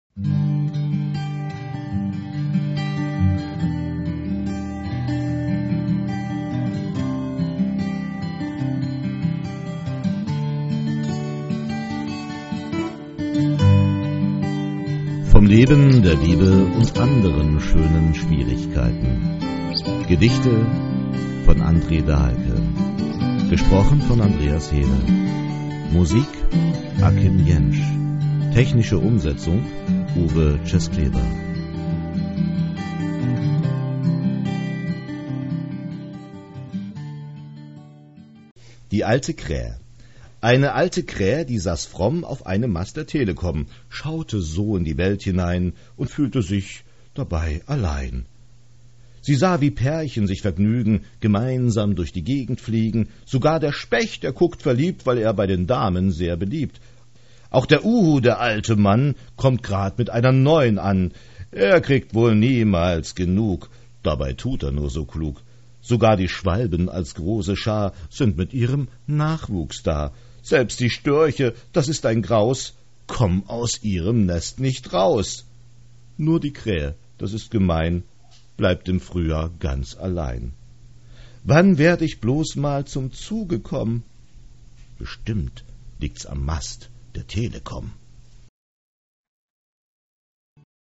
Vom Leben, der Liebe und anderen schönen Schwierigkeiten Audio-CD
Gesang und Musik